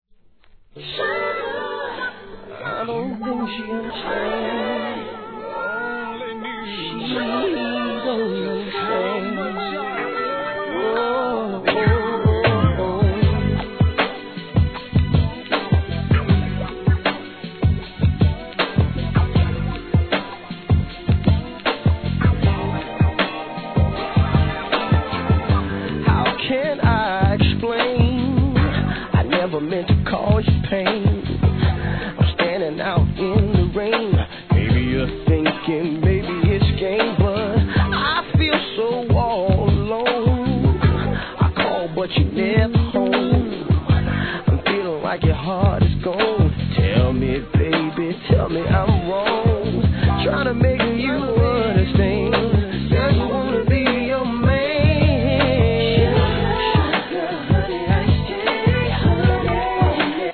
HIP HOP/R&B
1996年のミディアム・メロ〜R&B!!UK